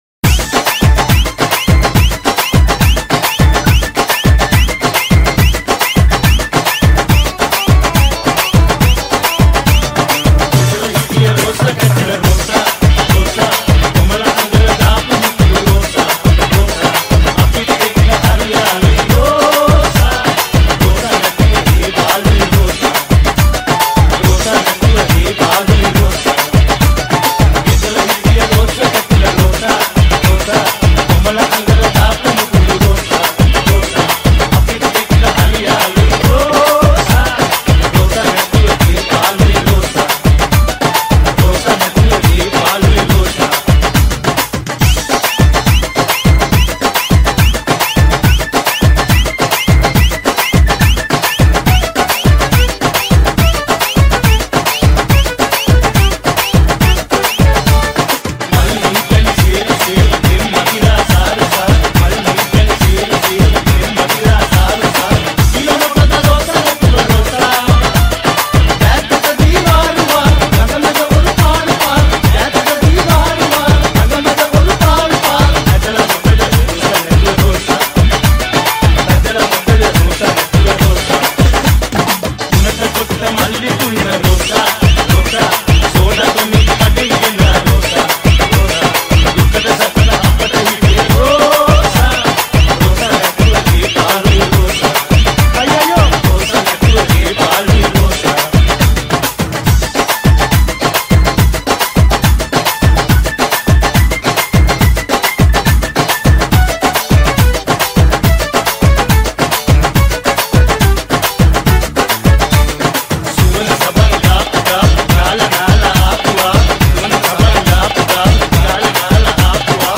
DJ Sinhala Remix 2020